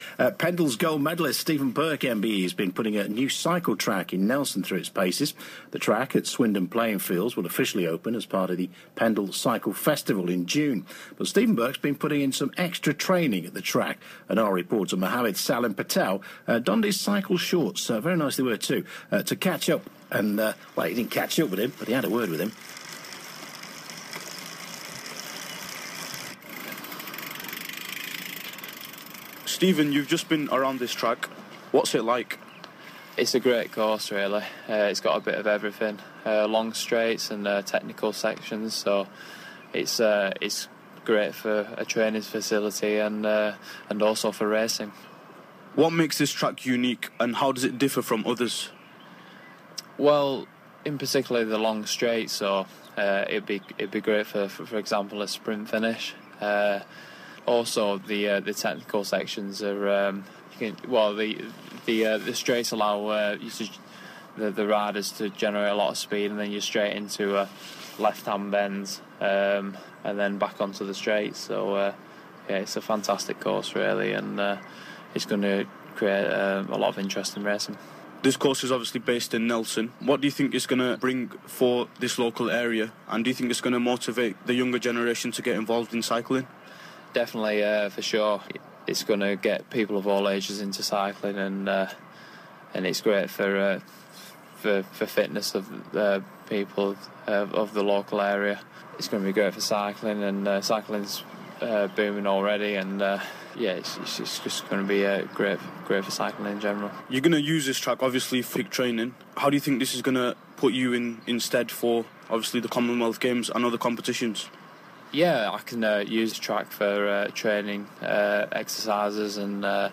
The interview was aired and produced for BBC Radio Lancashire.